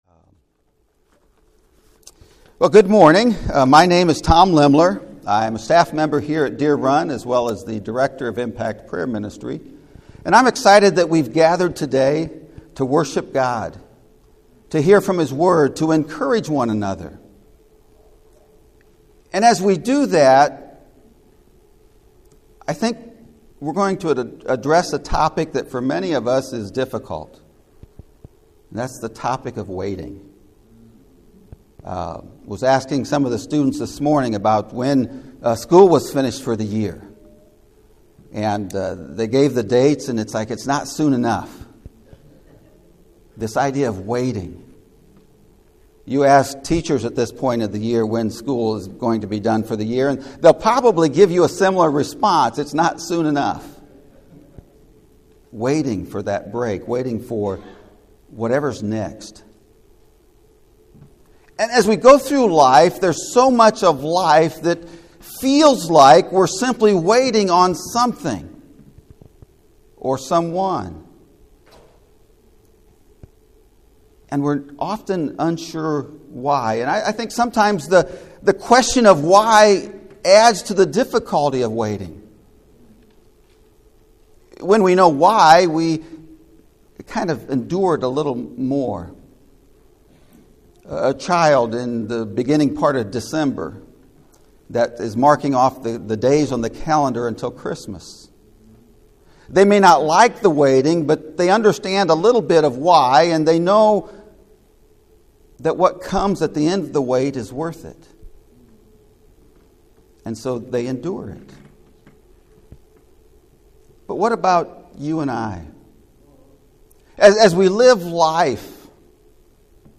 From Powerless to Pentecost: When God Says WAIT (Sermon Audio)